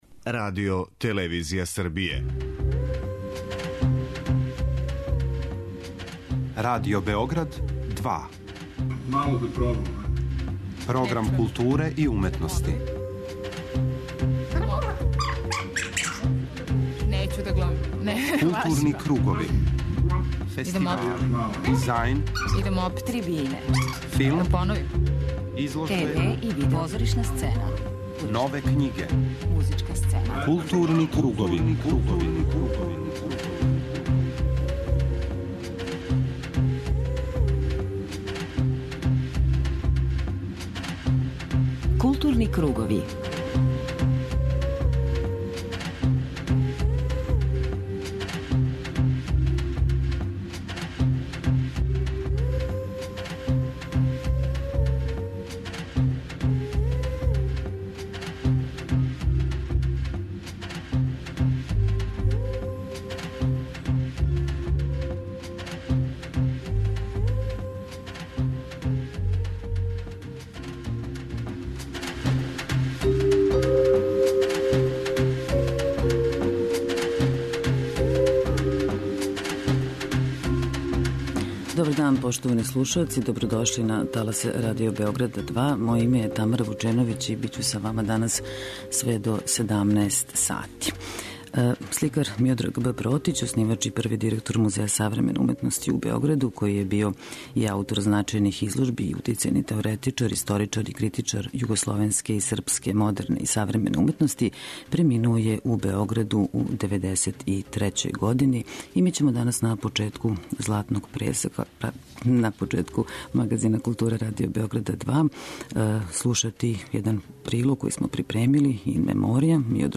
У тематском делу магазина културе нашу пажњу посвећујемо изложби прошлогодишњих лауреата награде за цртеж Фонда "Владимир Величковић" која је отворена синоћ у галерији "Хаос" у Београду. У Златном пресеку слушамо академика и сликара Владимира Величковићa, као и награђене младе ауторе.